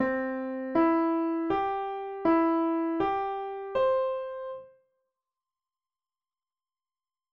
Singing up to the first inversion
3. Sing up to the major inversion: do mi so, mi so do